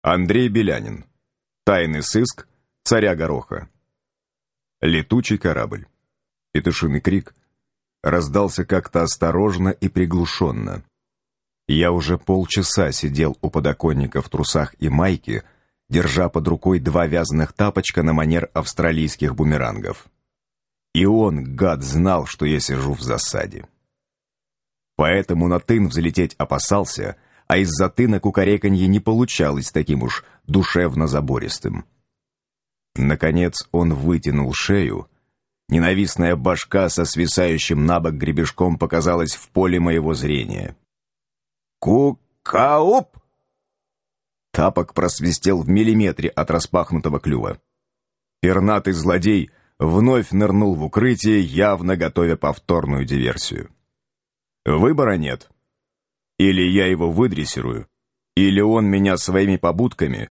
Аудиокнига Летучий корабль | Библиотека аудиокниг
Прослушать и бесплатно скачать фрагмент аудиокниги